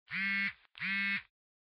Звук вибрації мобільного пристрою при отриманні смс